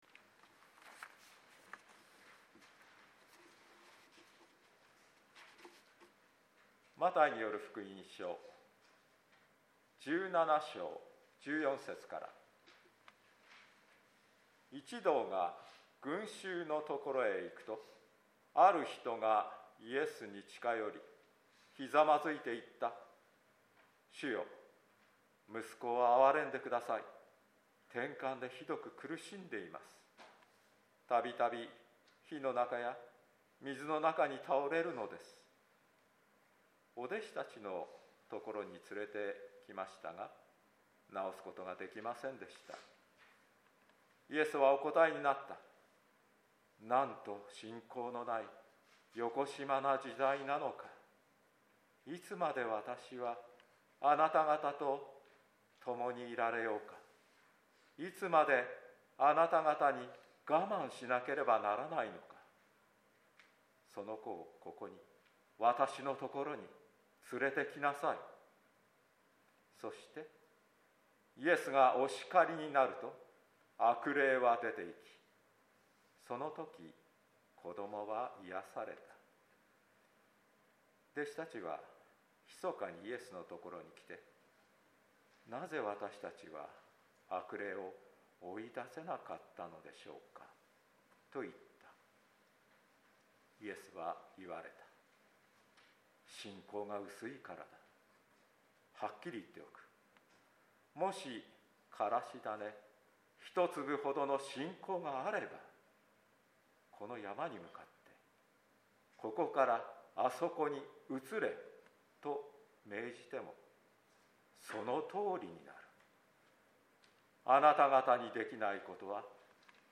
説教音声